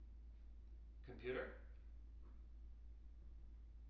wake-word
tng-computer-337.wav